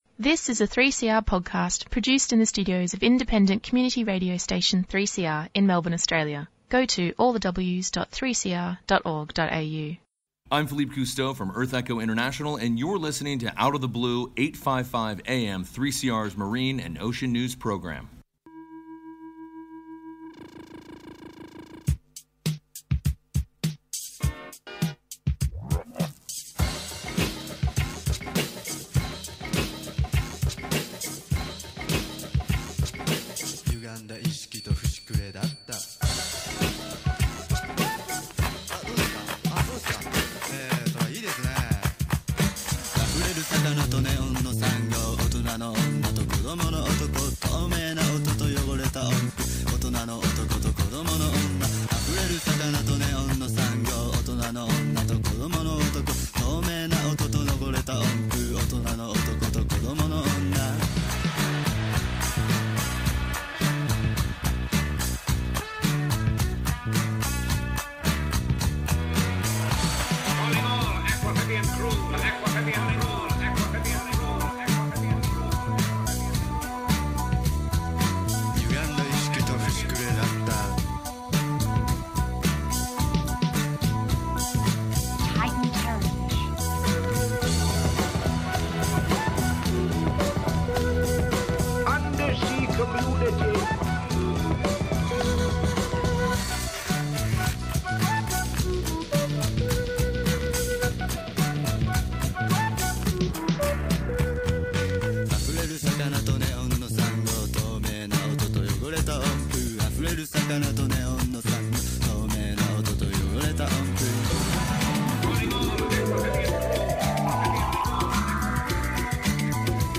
Tweet Out of the Blue Sunday 11:30am to 12:00pm Information about marine and coastal environments. News and interviews with marine scientists, campaigners and conservation workers. Presented by volunteer broadcasters who are passionate about marine environments, both local and across the world.